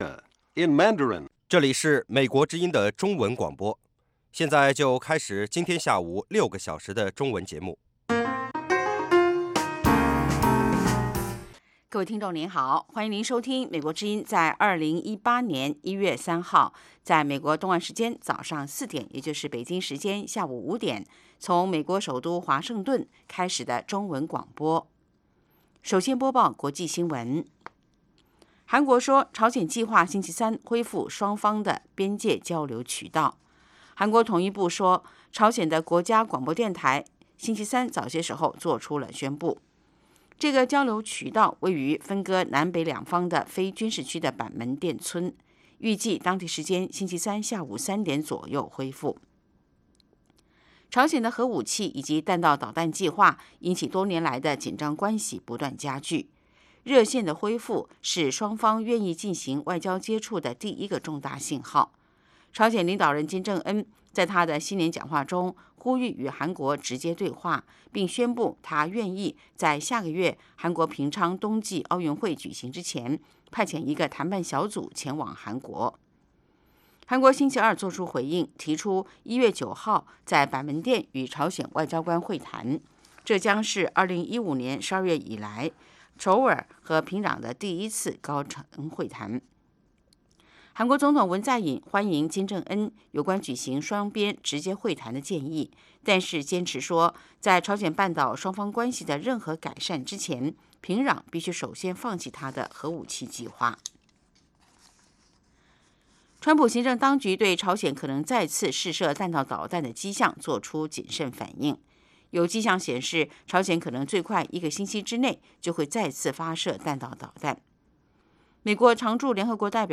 北京时间下午5-6点广播节目。广播内容包括国际新闻，收听英语，以及《时事大家谈》(重播)